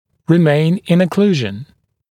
[rɪ’meɪn ɪn ə’kluːʒn][ри’мэйн ин э’клу:жн]оставаться в прикусе